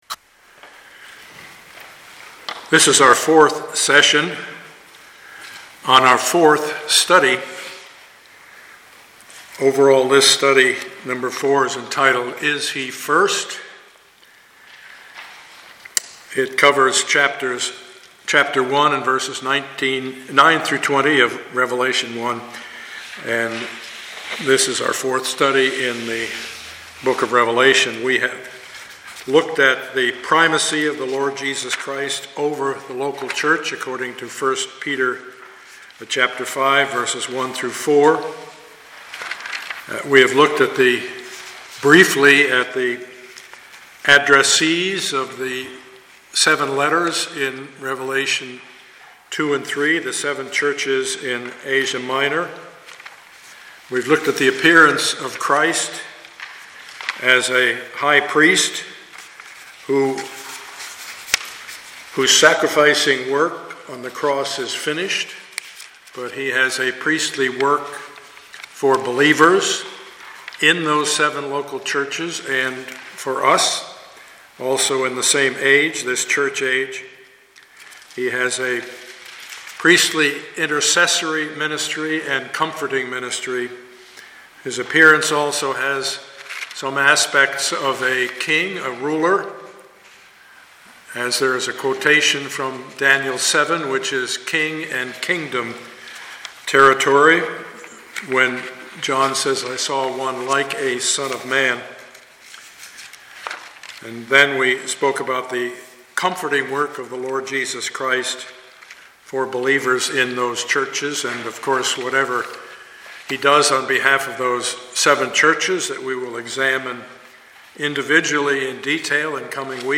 Passage: Revelation 1:9-20 Service Type: Sunday morning